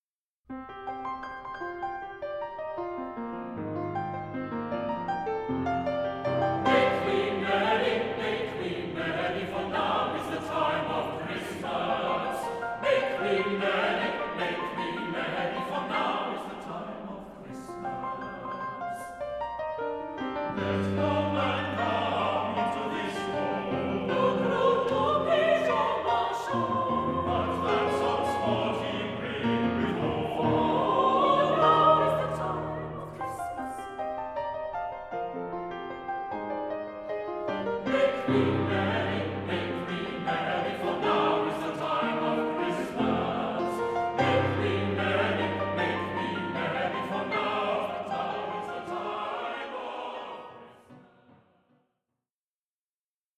SATB et Piano